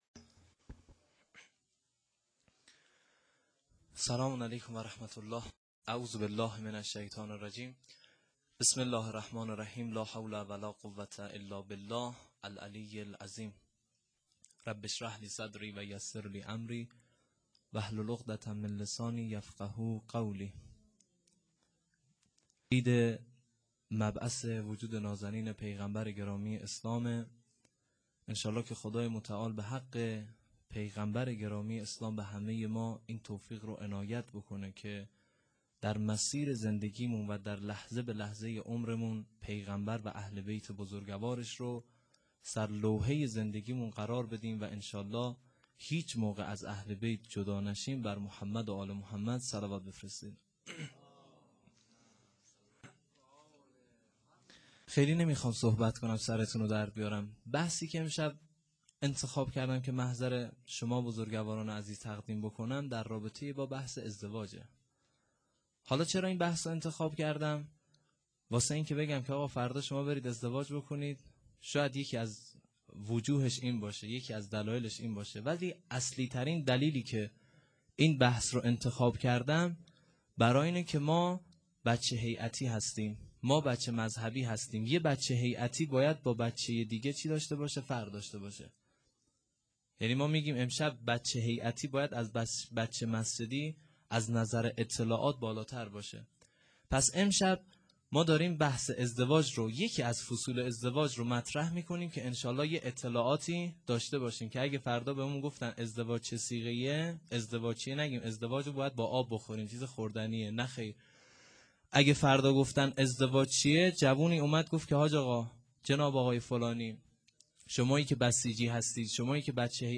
سخنرانی جشن.wma
سخنرانی-جشن.wma